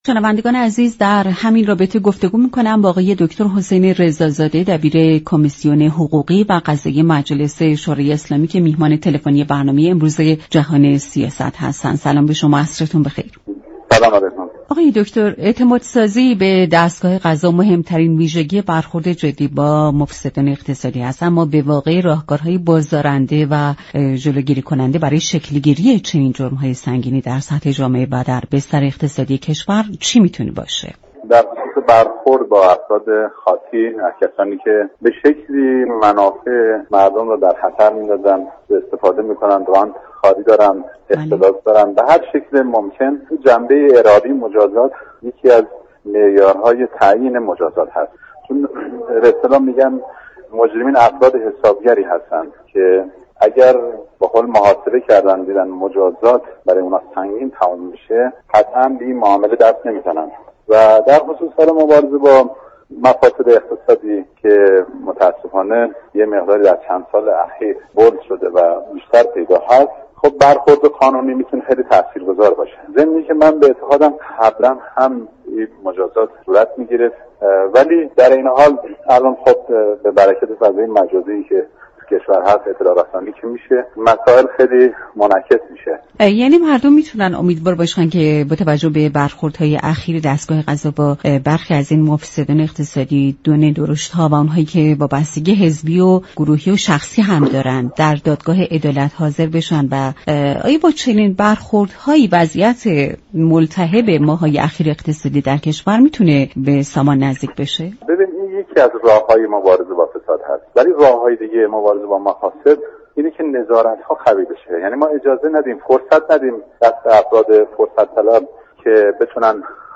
دبیر كمیسیون حقوقی قضایی مجلس در گفت و گو با رادیو ایران گفت.